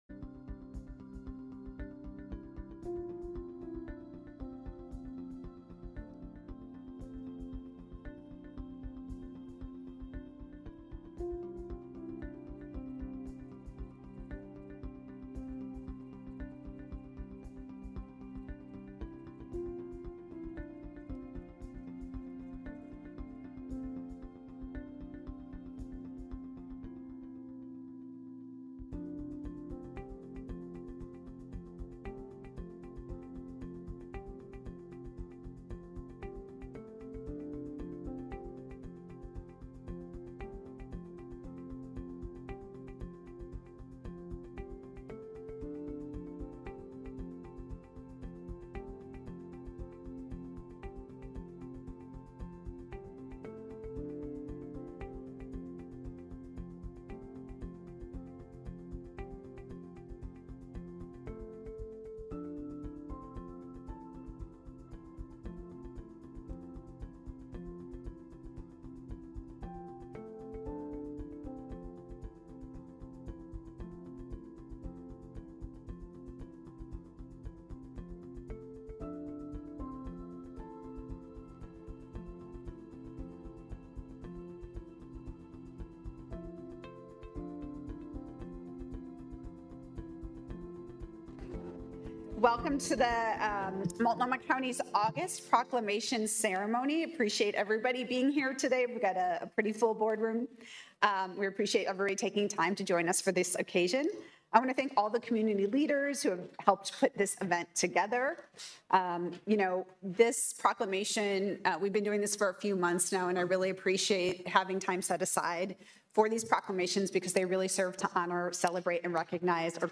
Proclamation Signing Ceremony - Aug 07, 2025 by Multnomah County, OR: New View Audio Podcast